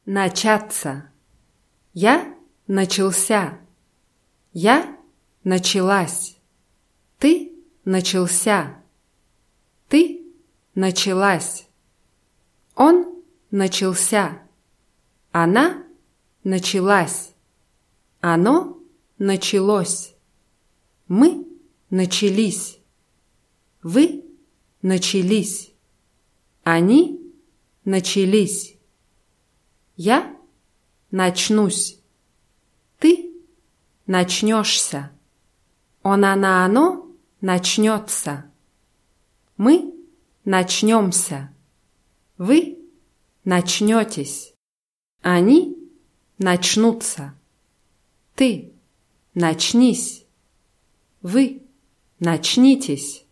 начаться [natschátsa]